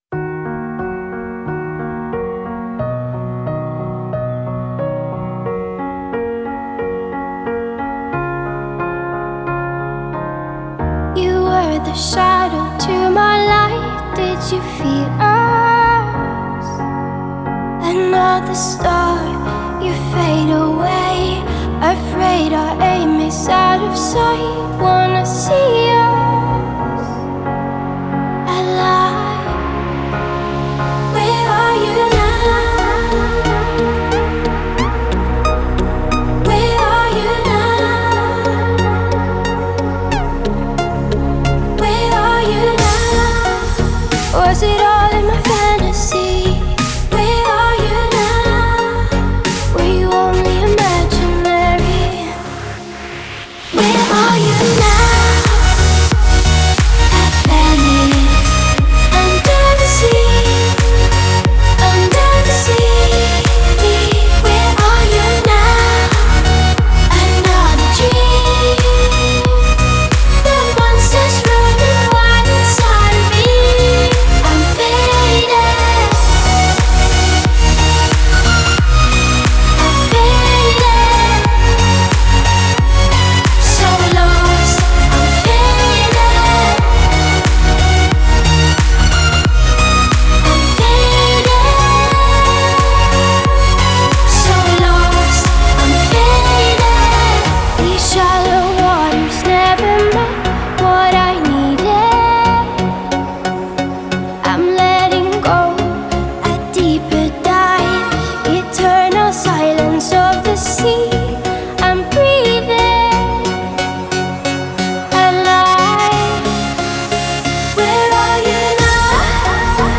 Главная » Файлы » Клубная Музыка Категория